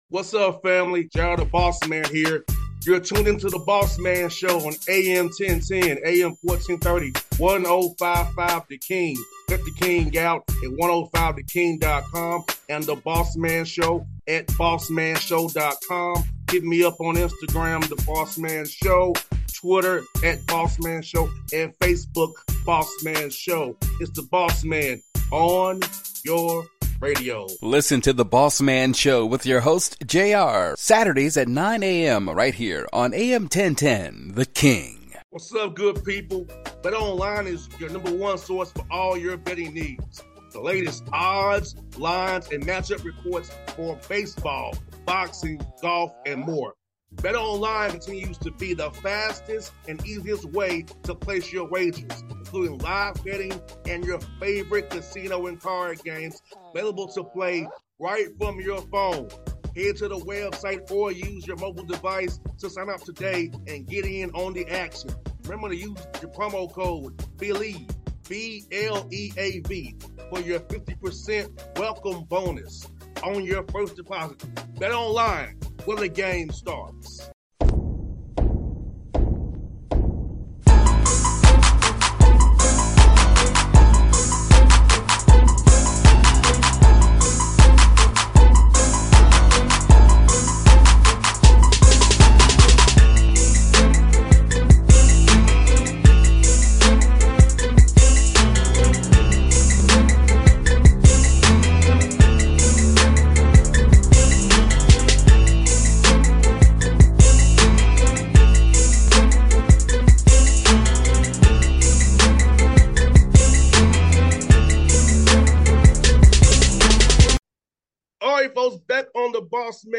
Marcus Freeman Interview